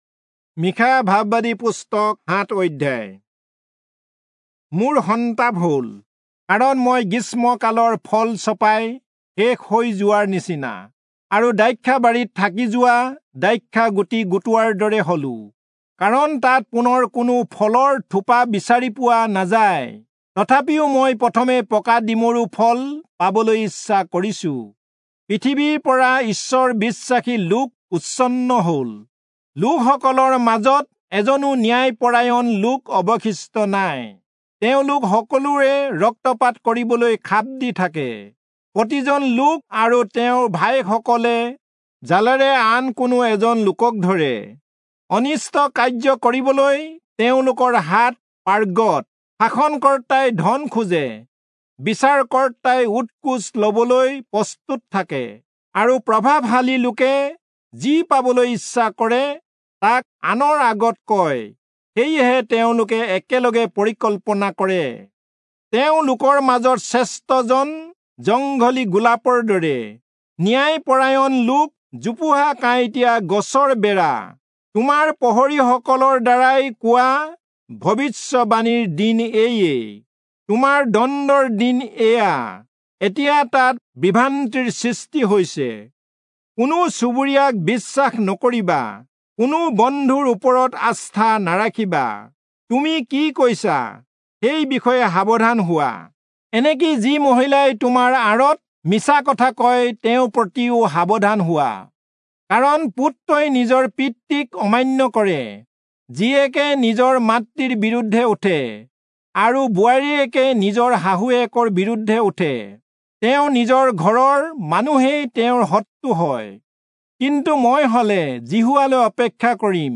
Assamese Audio Bible - Micah 7 in Tev bible version